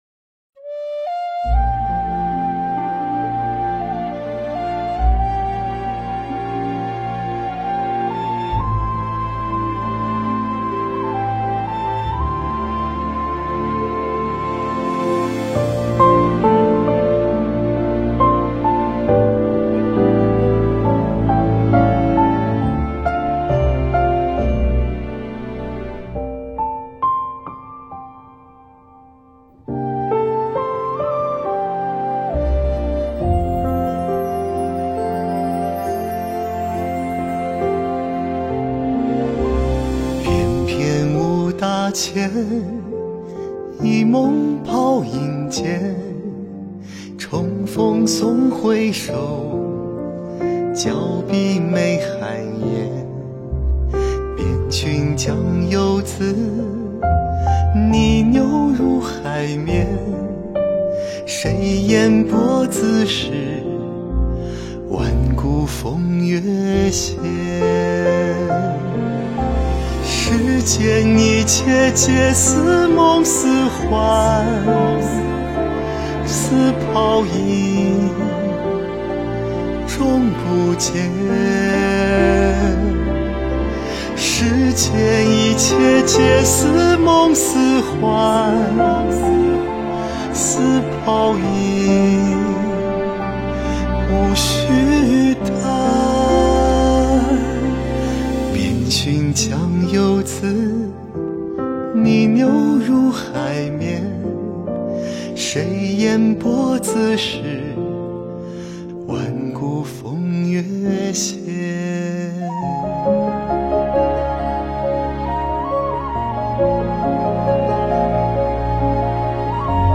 佛音 凡歌 佛教音乐 返回列表 上一篇： 我佛慈悲